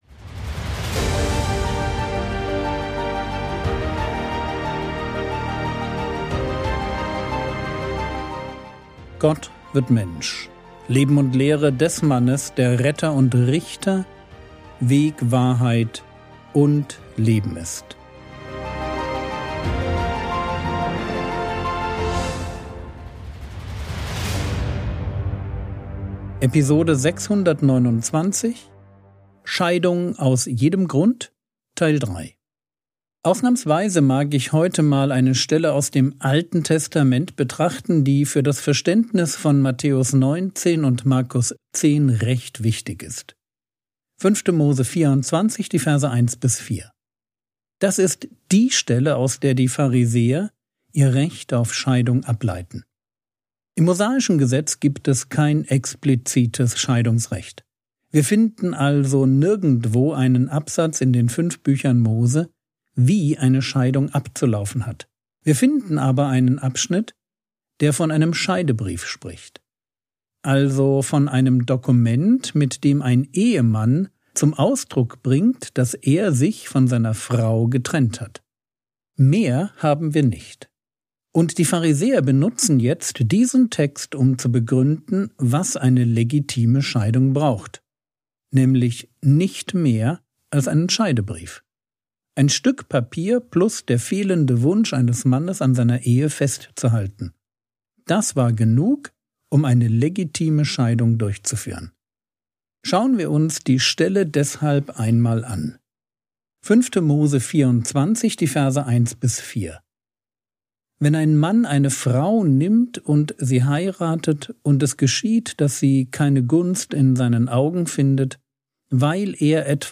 Episode 629 | Jesu Leben und Lehre ~ Frogwords Mini-Predigt Podcast